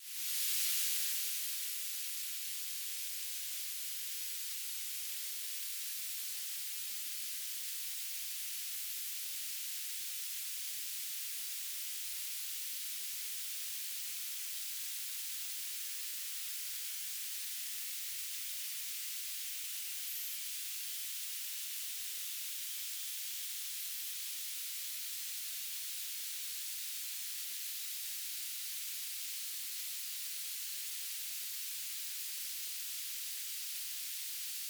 "transmitter_description": "BPSK1k2 AX.25 TLM",
"transmitter_mode": "BPSK",